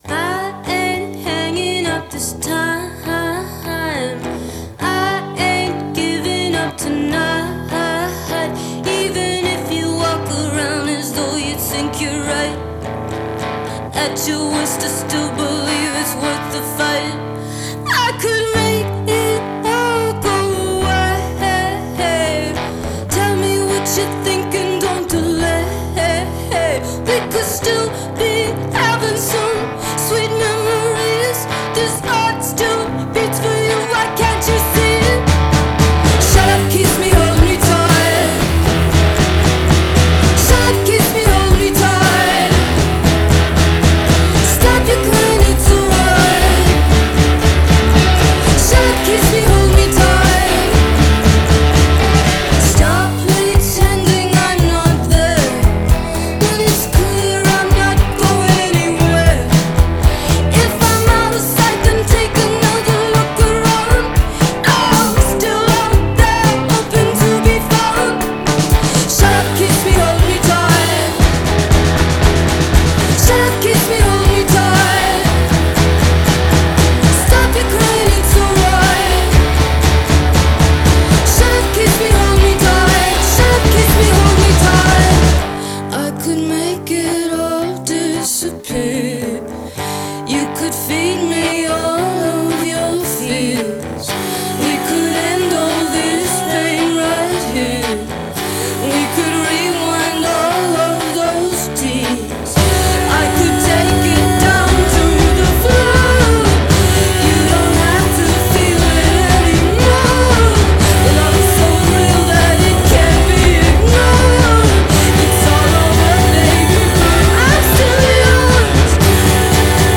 lush, indie folk-rock